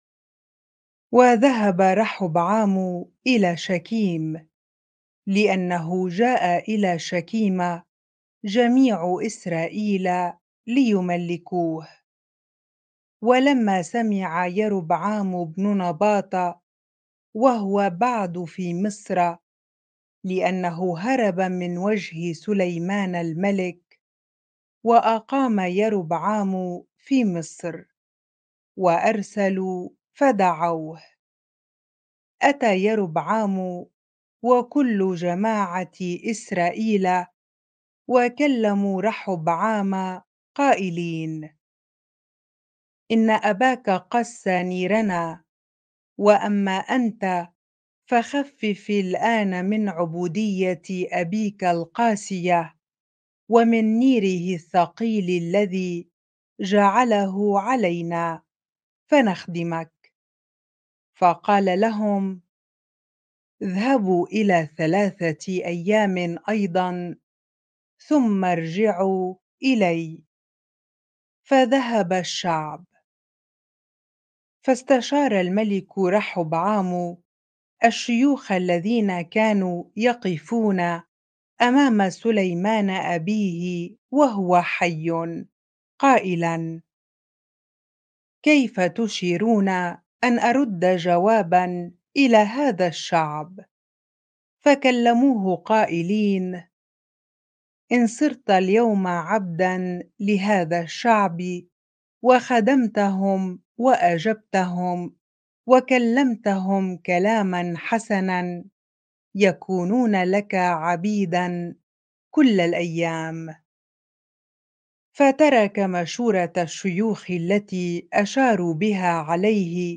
bible-reading-1 Kings 12 ar